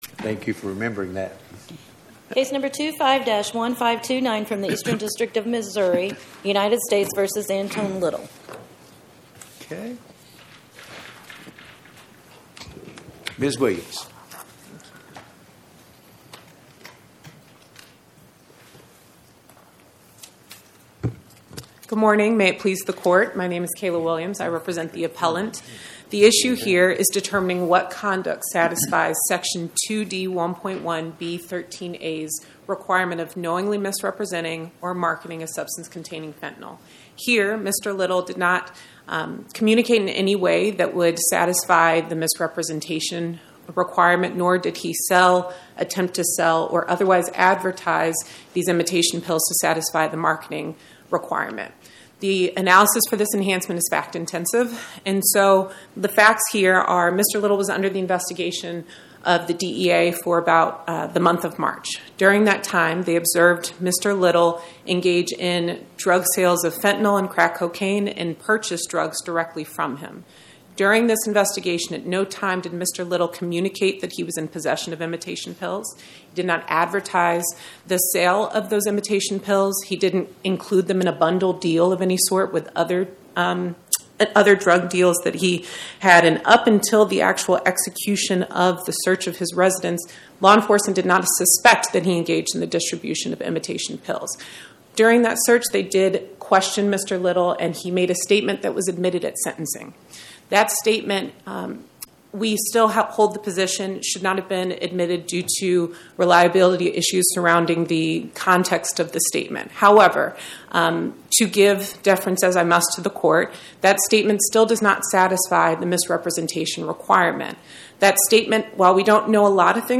Fri Jan 16 2026 Description: Oral argument argued before the Eighth Circuit U.S. Court of Appeals on or about 01/16/2026